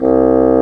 Index of /90_sSampleCDs/Roland LCDP04 Orchestral Winds/WND_Bassoons/WND_Bassoon 4
WND BSSN C2.wav